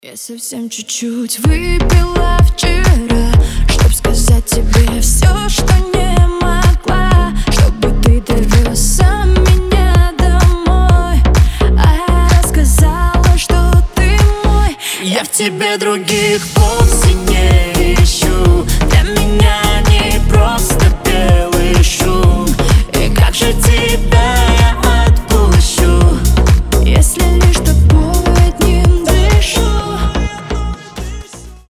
• Качество: 320, Stereo
дуэт
красивый женский голос